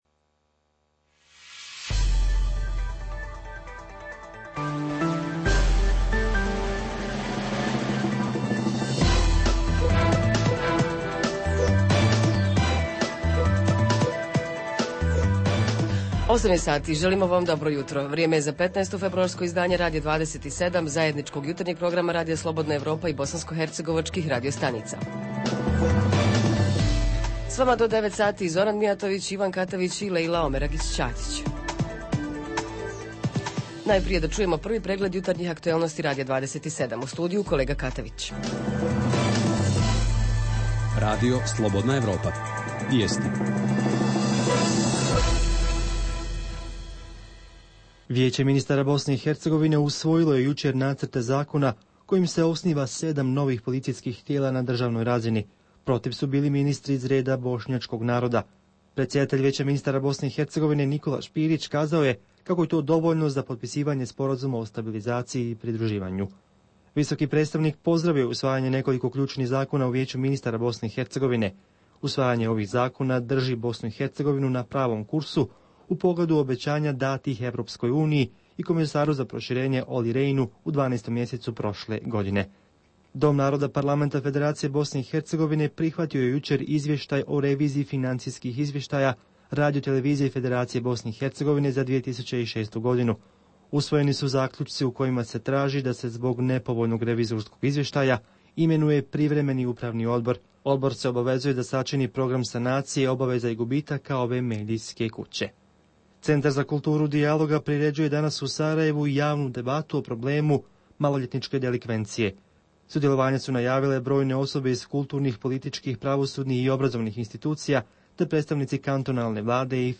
Jutarnji program za BiH koji se emituje uživo. Tema emisije je ravnopravnost spolova u našoj zemlji.
Redovni sadržaji jutarnjeg programa za BiH su i vijesti i muzika.